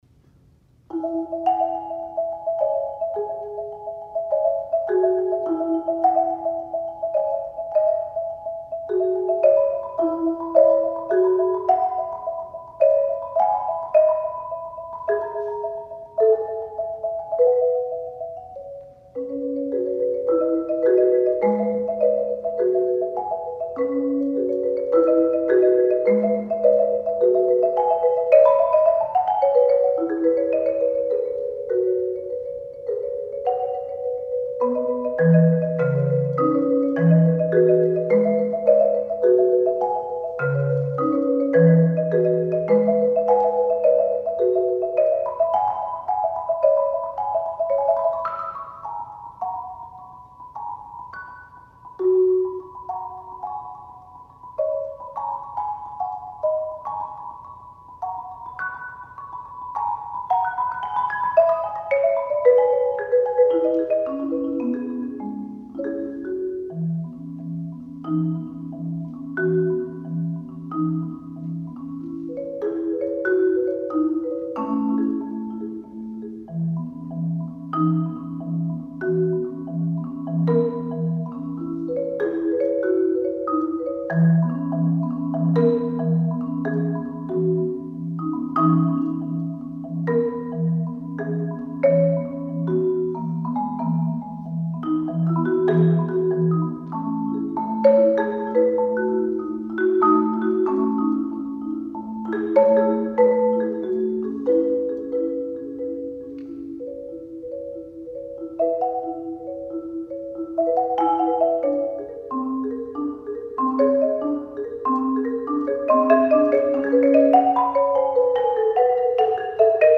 Genre: Solo Marimba
Marimba (5-octave)